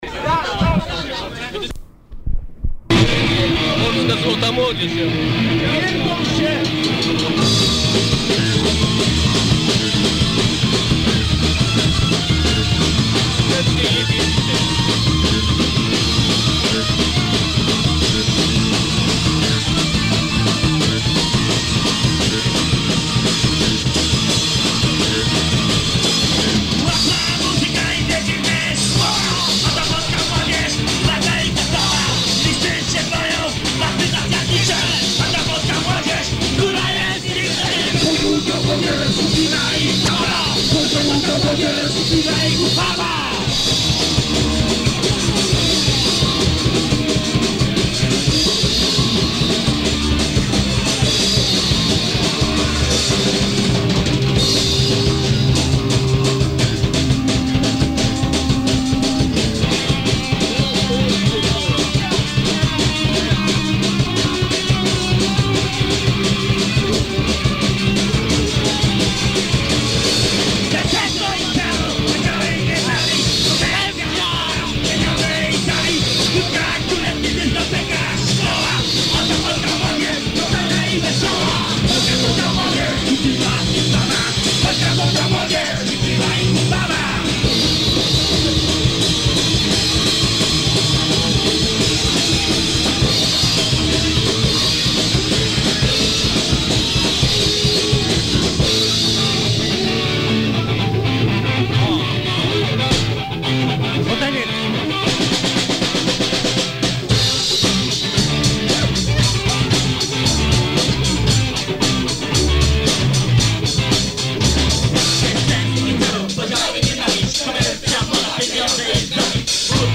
Nagrań dokonano za pomocą decka i 2 mikrofonów.